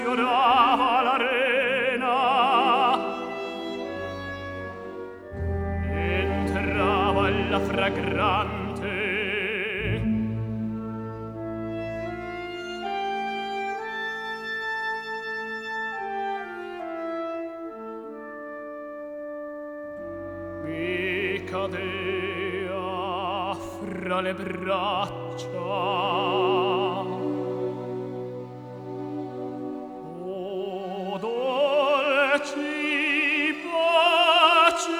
Classical Crossover
Жанр: Классика